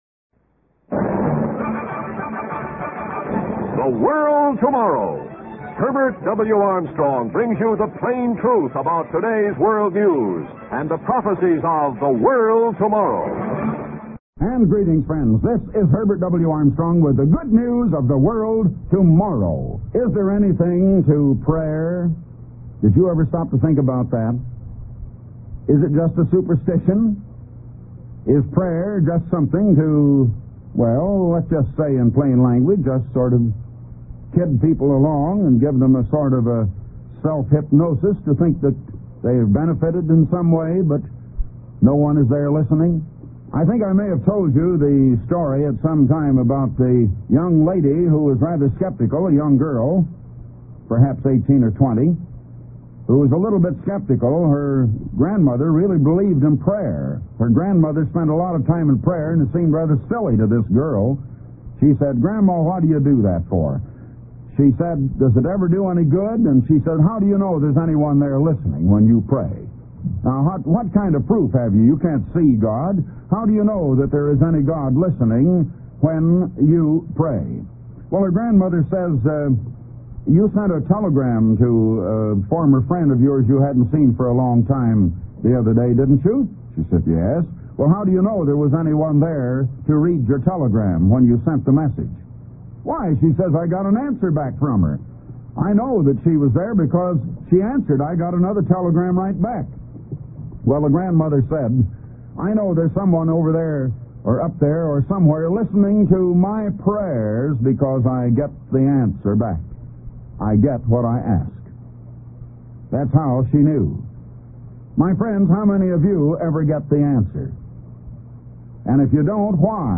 Type: Radio Broadcast